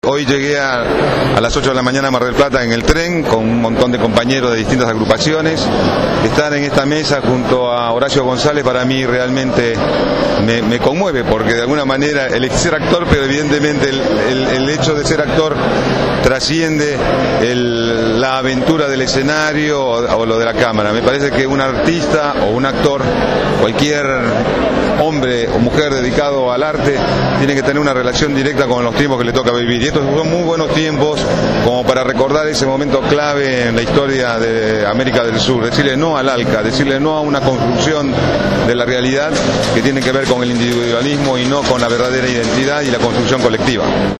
El actor Juan Palomino participó de la convocatoria para conmemorar el sexto aniversario del rechazo al ALCA